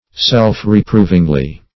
Self-reprovingly \Self`-re*prov"ing*ly\, adv. In a self-reproving way.